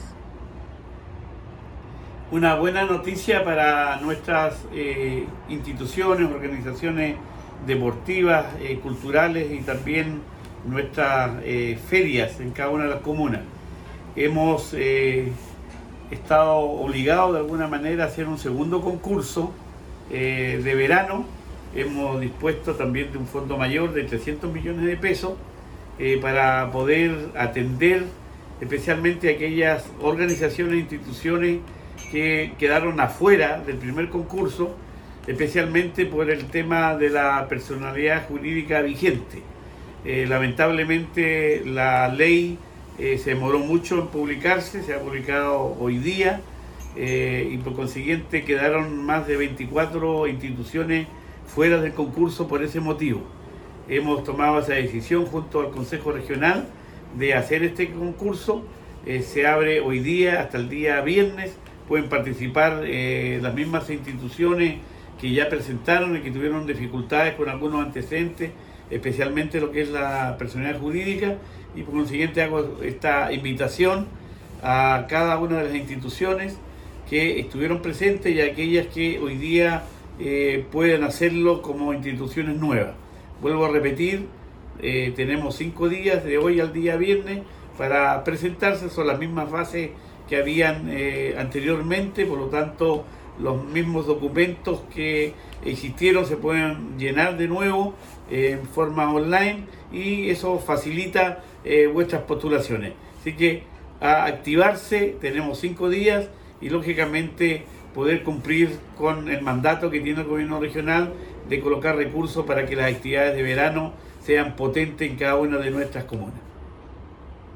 Así lo detalló el Gobernador Regional, Luis Cuvertino.
Cuña_Gobernador_segundo-llamado-fondos-de-veranos.m4a